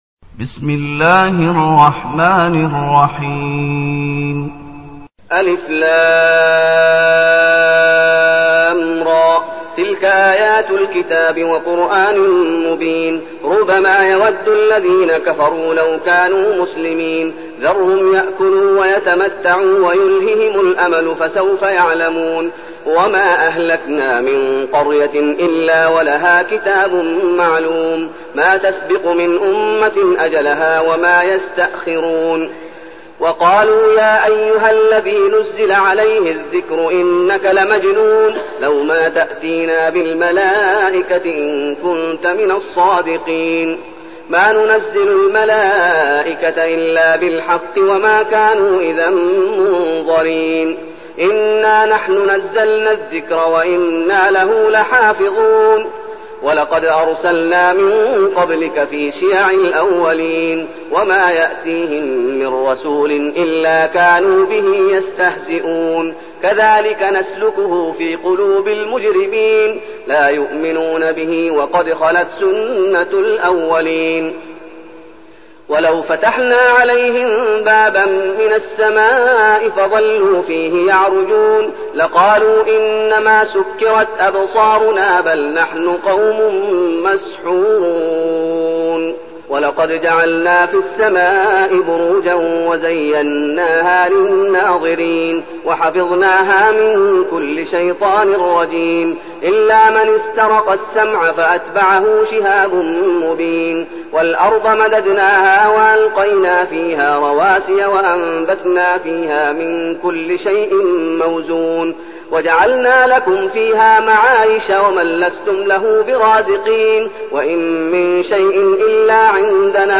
المكان: المسجد النبوي الشيخ: فضيلة الشيخ محمد أيوب فضيلة الشيخ محمد أيوب الحجر